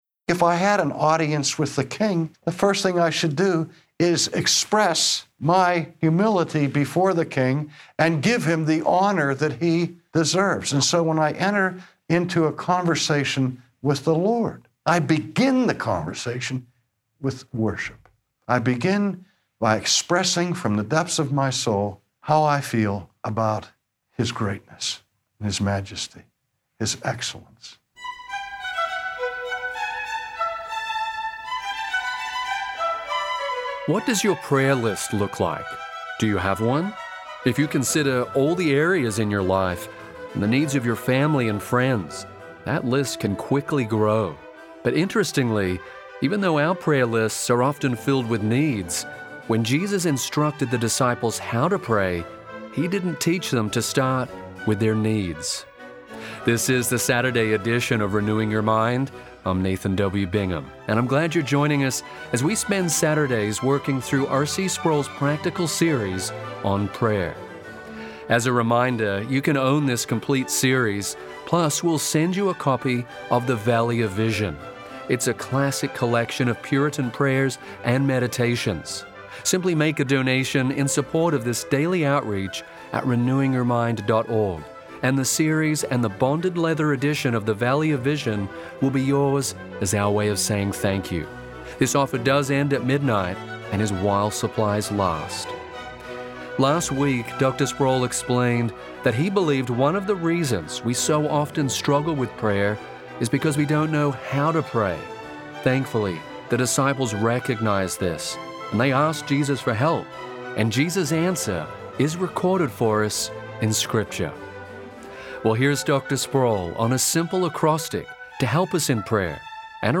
What should be the posture of our hearts and minds when we pray to the Lord? Today, R.C. Sproul teaches that prayer ought to begin on a note of adoration for God.